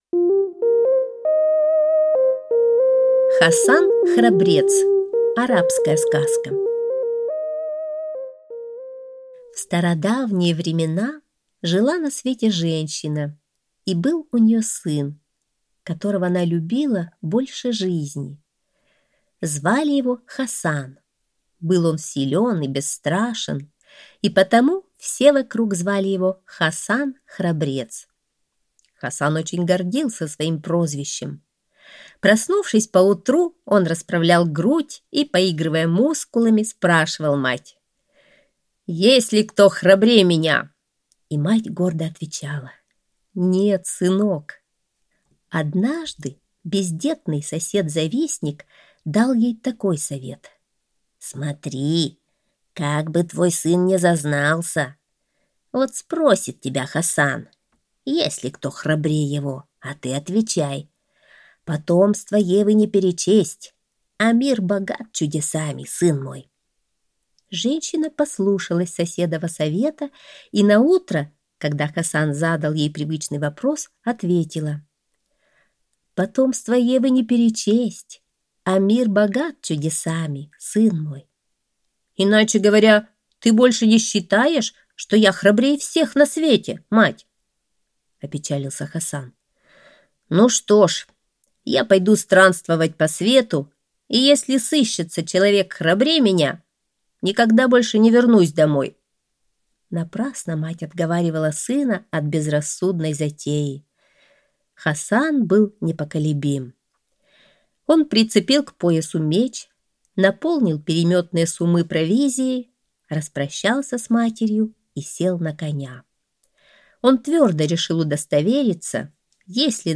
Хасан-храбрец - арабская аудиосказка - слушать онлайн